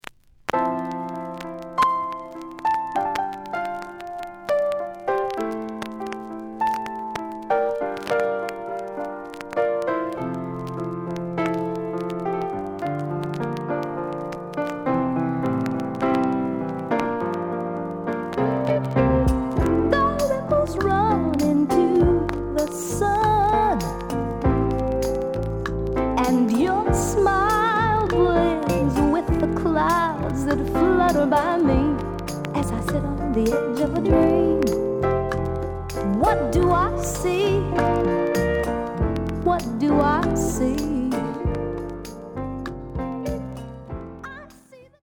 The audio sample is recorded from the actual item.
●Genre: Soul, 70's Soul
Some click noise on both sides due to scratches.)